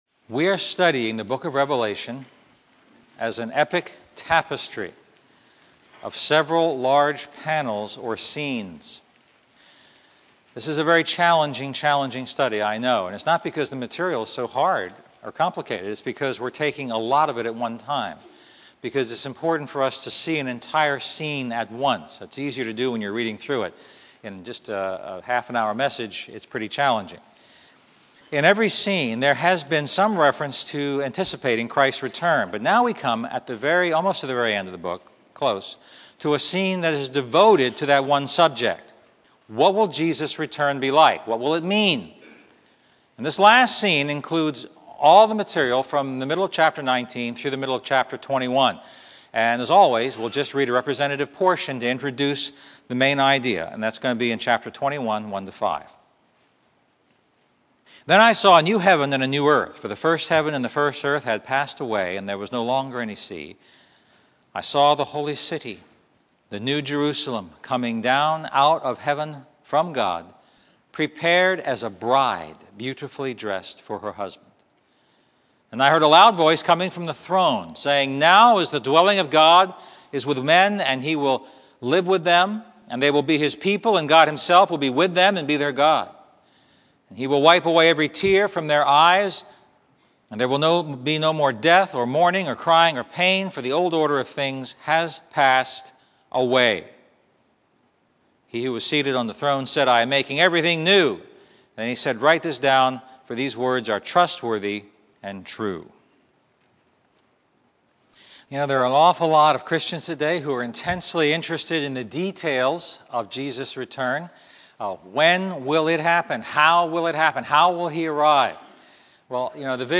Message
Expository